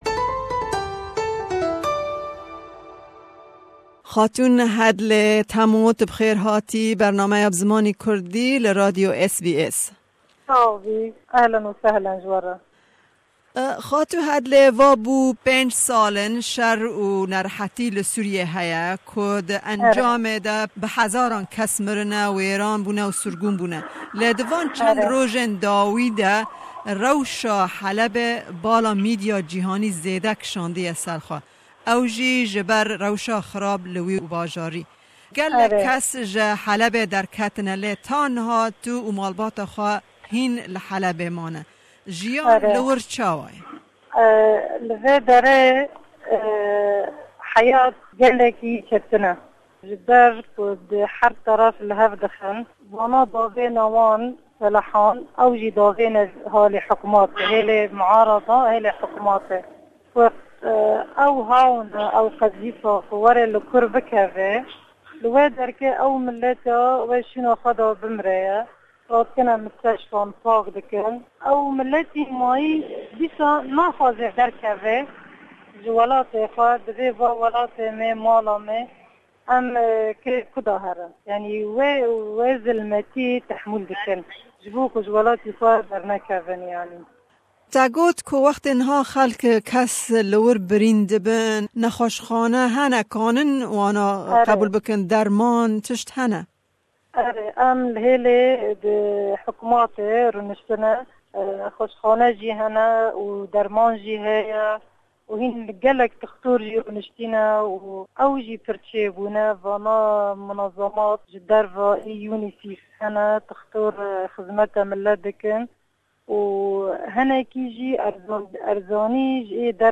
Me derbarî rewsha di nav Helebê de hevpeyvînek